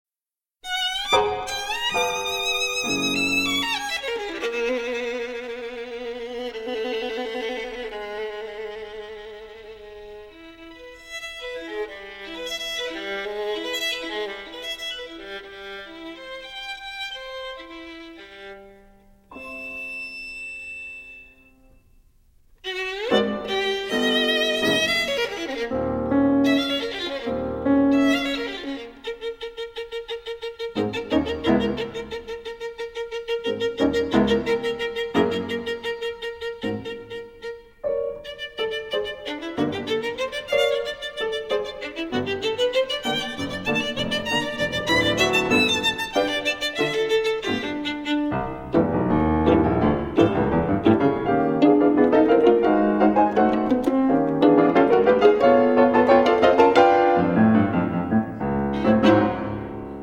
Violin
Sonata for violin and piano
piano